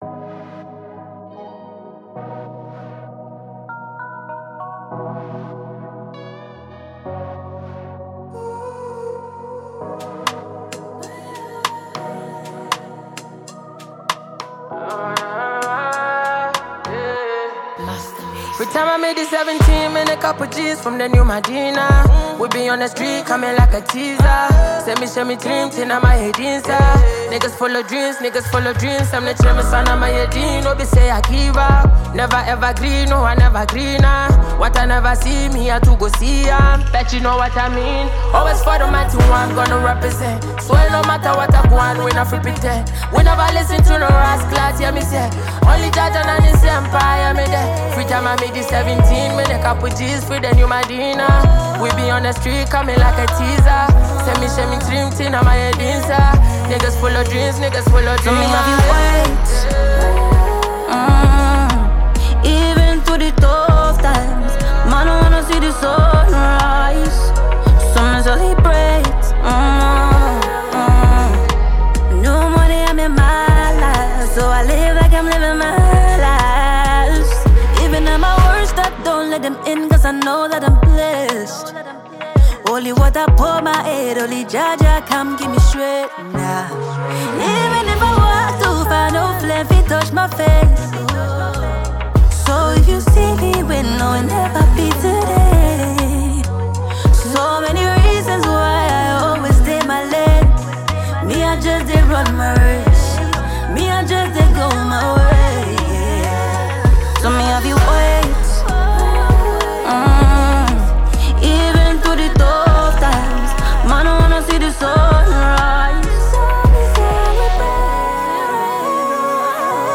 Ghanaian female artiste